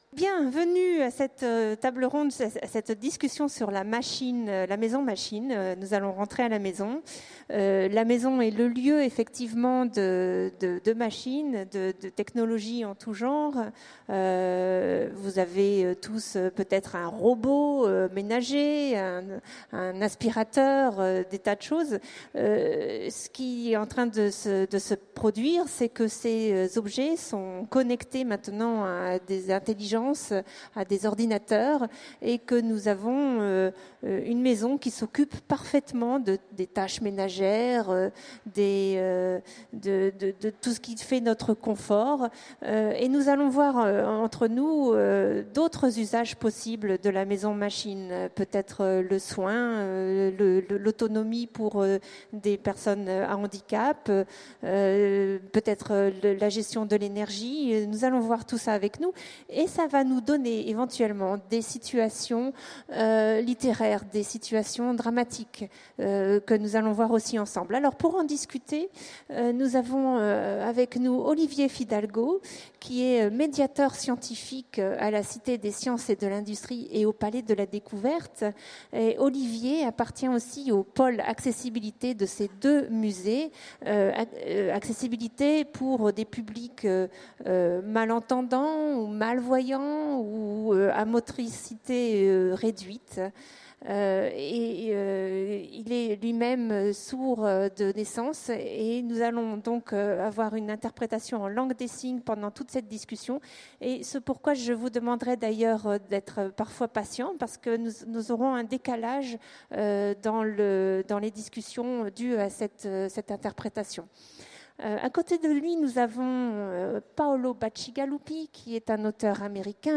Utopiales 2016 : Conférence La maison machine